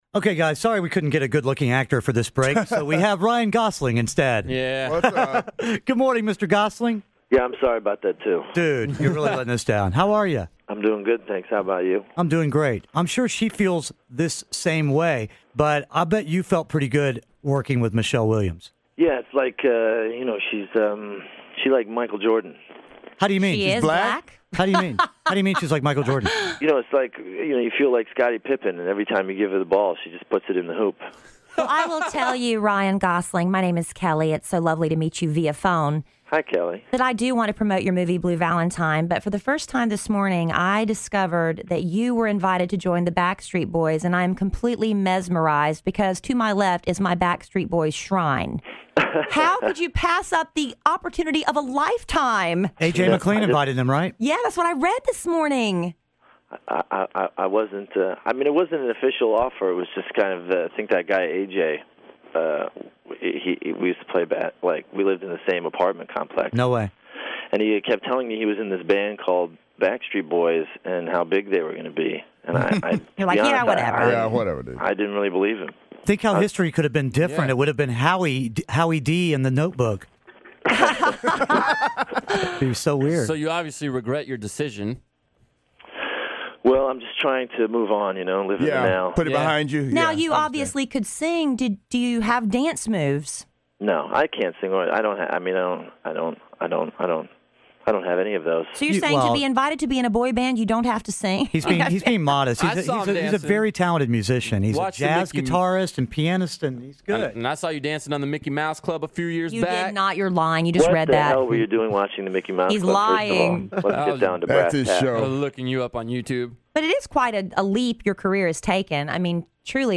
Ryan Gosling Interview
Kidd Kraddick in the Morning interviews Ryan Gosling!